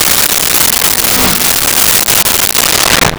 Speed Bike Fast By
Speed Bike Fast By.wav